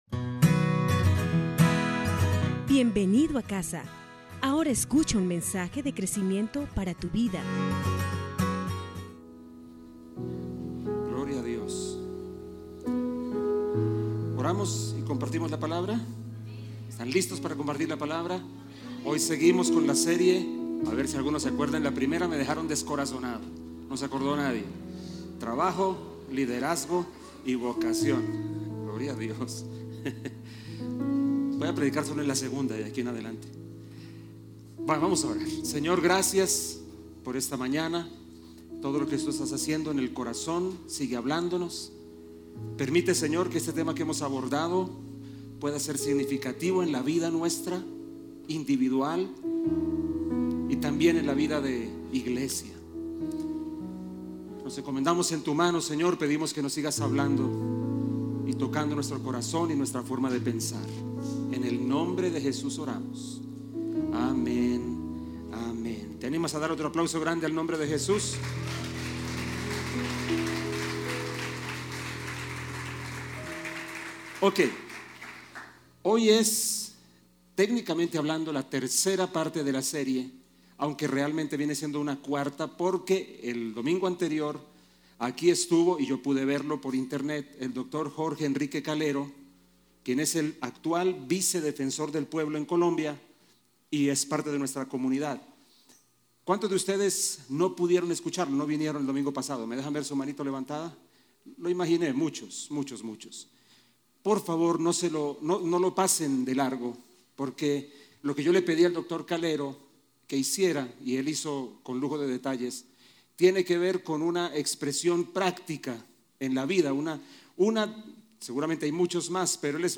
Lección 1: Trabajo, liderazgo y vocación - Parte 4 MP3